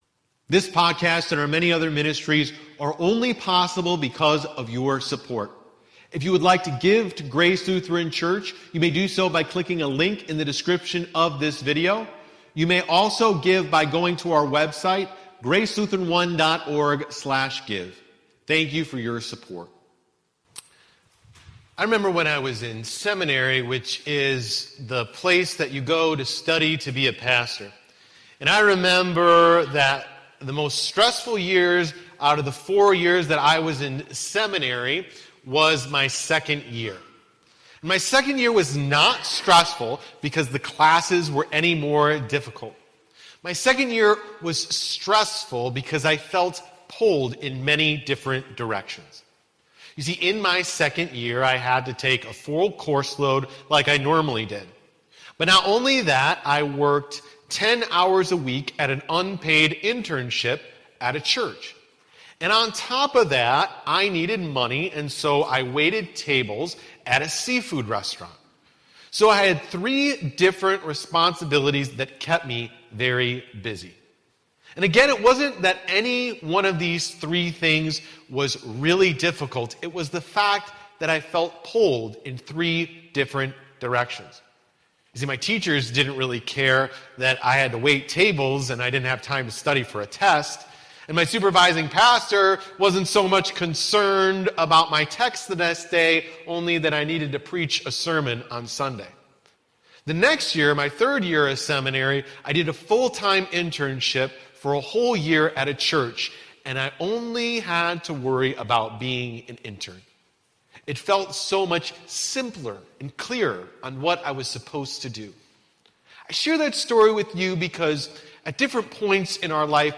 Sermon 4.3.22